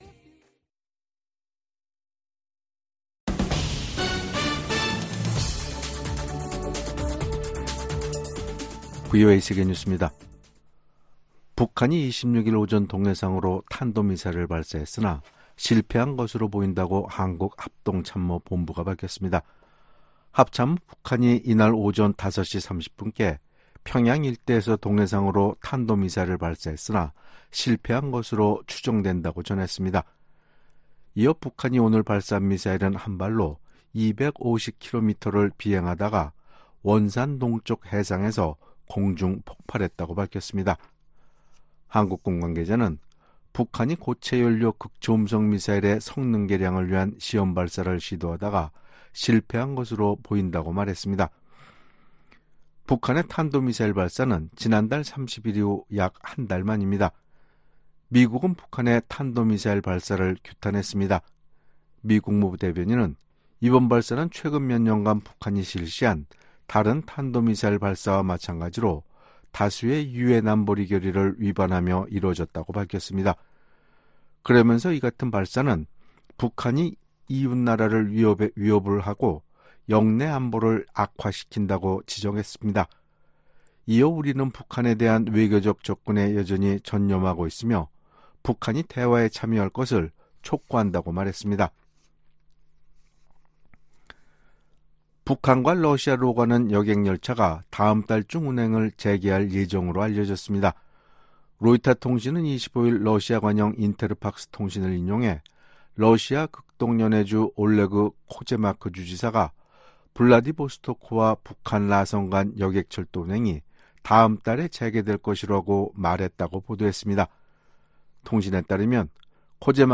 VOA 한국어 간판 뉴스 프로그램 '뉴스 투데이', 2024년 6월 26일 3부 방송입니다. 북한이 동해상으로 극초음속 미사일로 추정되는 발사체를 쏘고 이틀째 한국을 향해 오물 풍선을 살포했습니다. 미국 정부는 북한의 탄도미사일 발사가 다수의 유엔 안보리 결의 위반이라며 대화에 복귀할 것을 북한에 촉구했습니다. 미국 국방부는 북한이 우크라이나에 병력을 파견할 가능성에 대해 경계를 늦추지 않고 있다는 입장을 밝혔습니다.